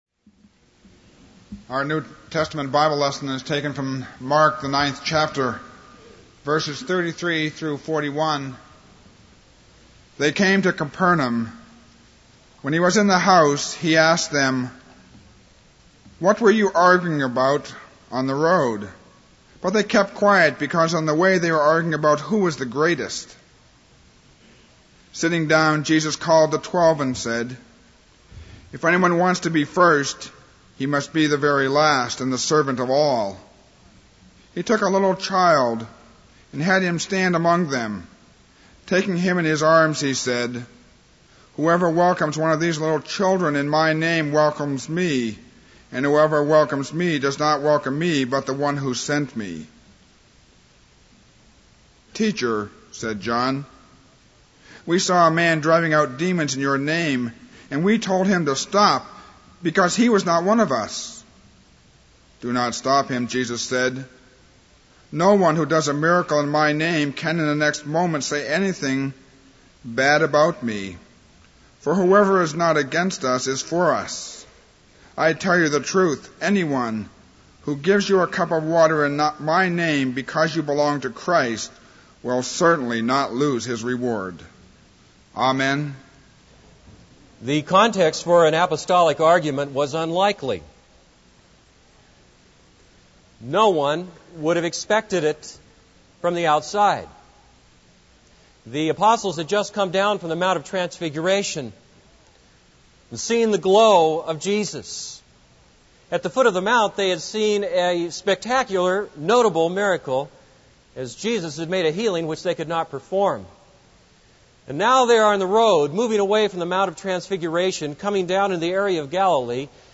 This is a sermon on Mark 9:33-41.